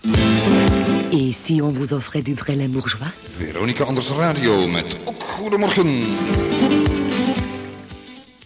Jingle